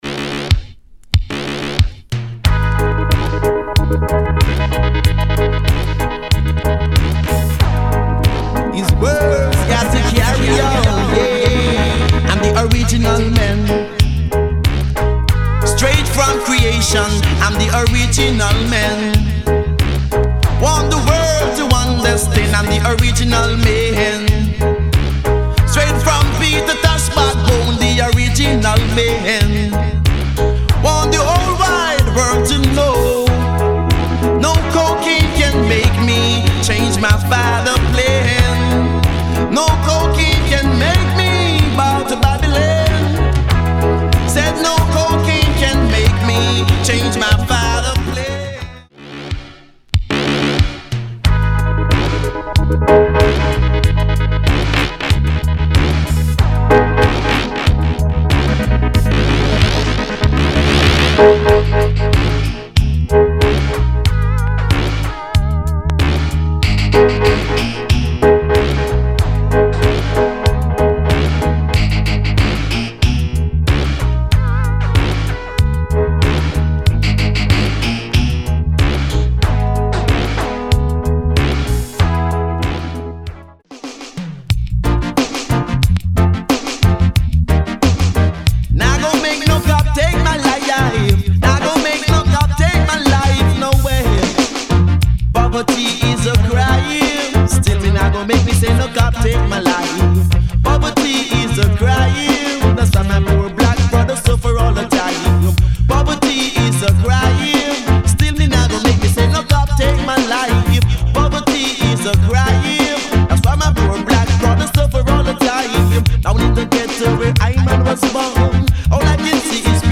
ALL our sound files are recorded flat with no EQ added.